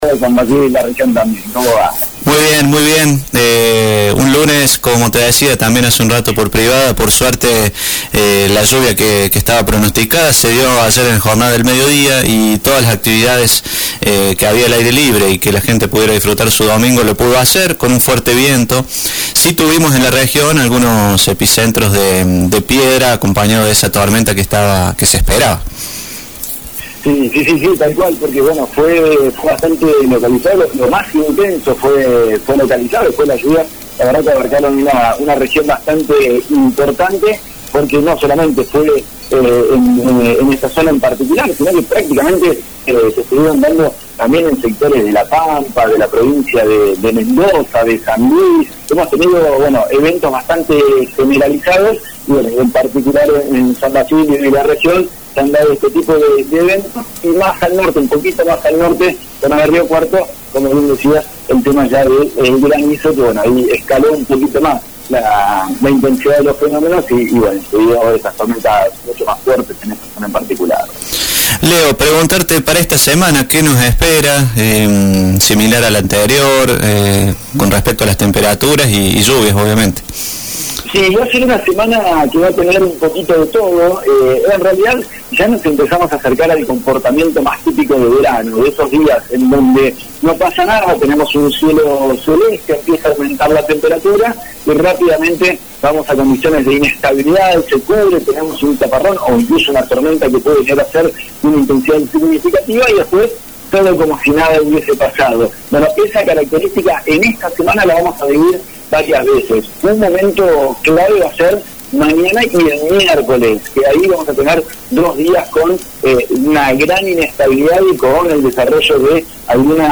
pronóstico del tiempo